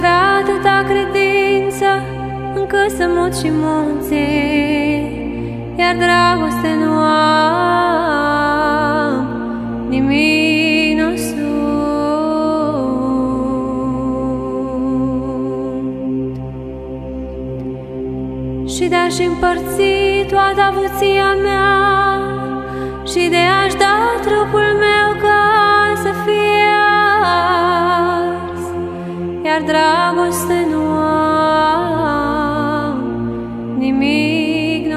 Жанр: Нью-эйдж